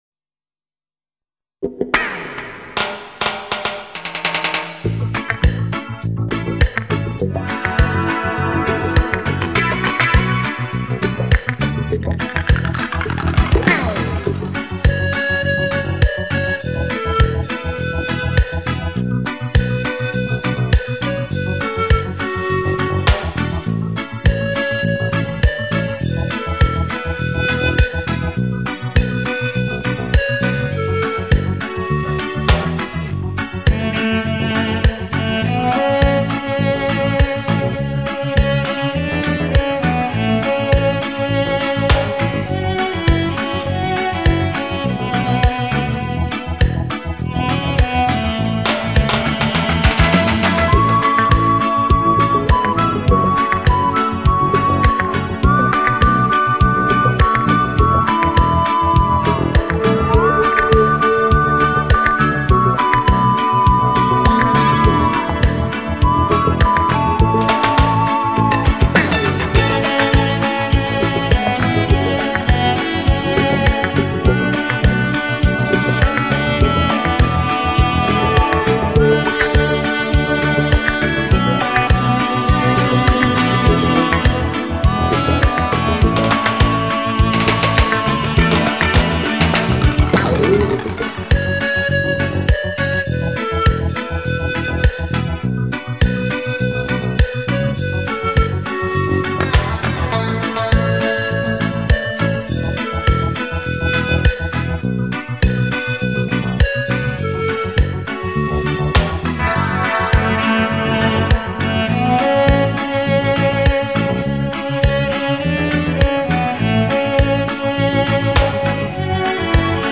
陶笛与大提琴的动心组合，自由浪漫、热情奔腾的音律随想，这是一张令你无法抗拒的冠军演奏专辑。